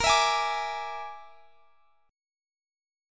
Bb7b5 Chord
Listen to Bb7b5 strummed